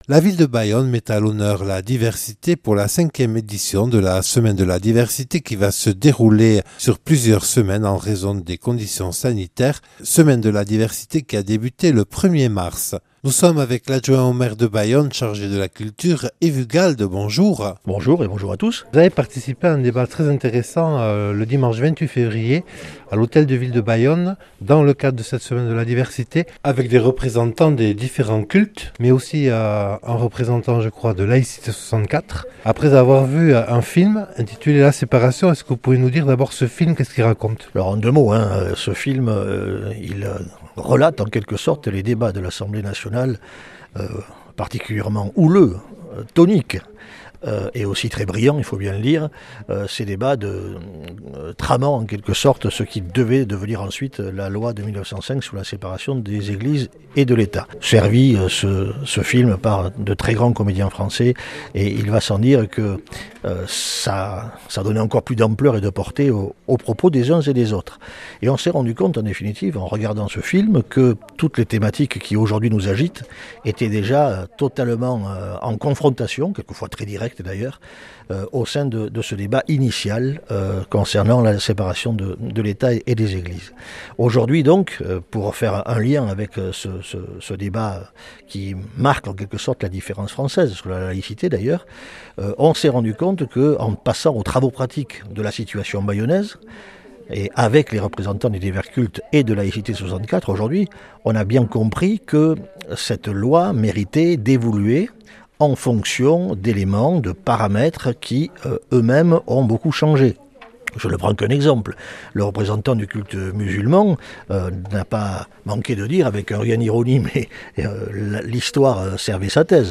Yves Ugalde, adjoint au maire chargé de la Culture.